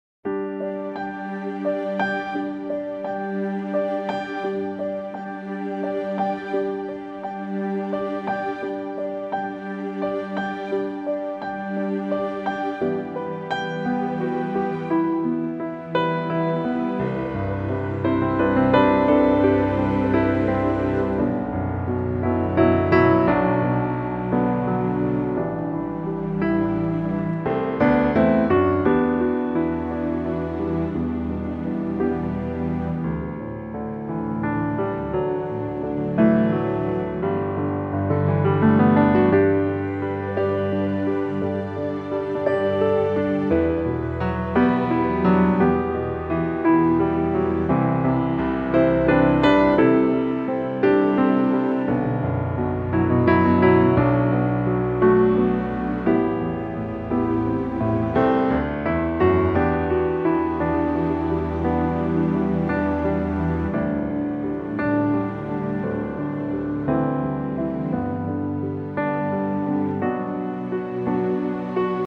key - G to Bb - vocal range - E to C